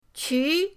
qu2.mp3